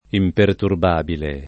[ imperturb # bile ]